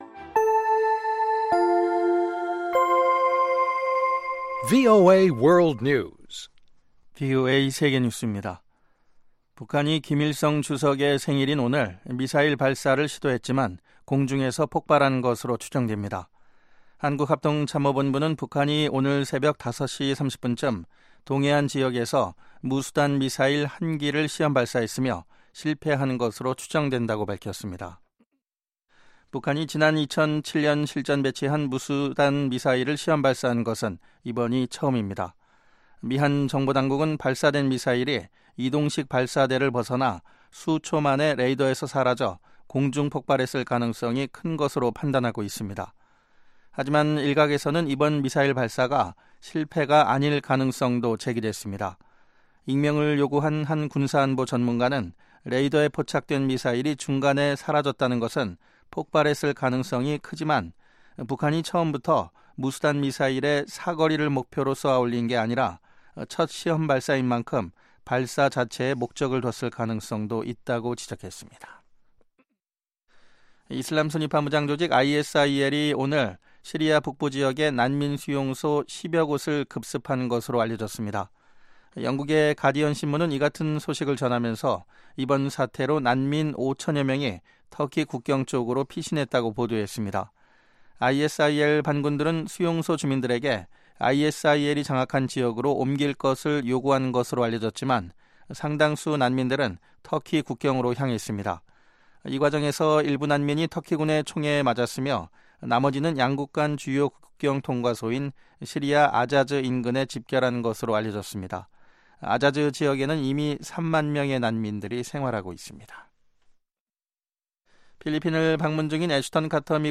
VOA 한국어 방송의 간판 뉴스 프로그램 '뉴스 투데이' 2부입니다. 한반도 시간 매일 오후 9:00 부터 10:00 까지, 평양시 오후 8:30 부터 9:30 까지 방송됩니다.